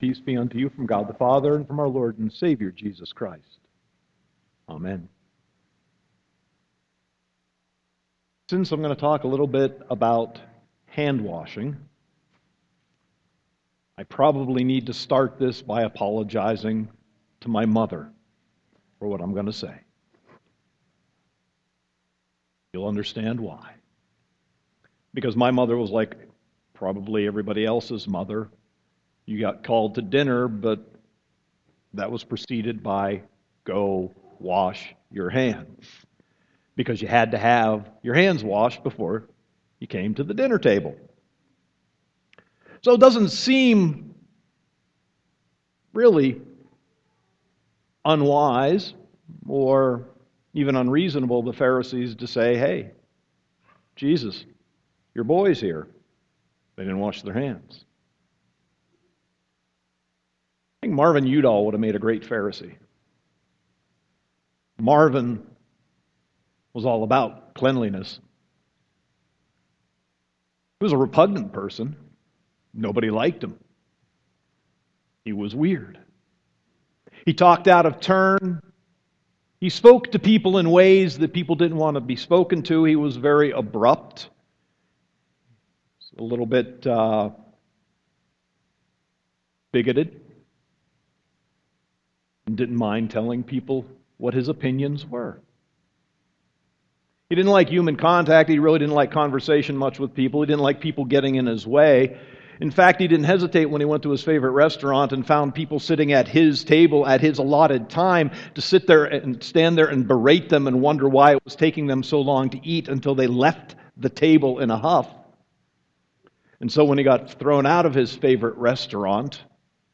Sermon 8.30.2015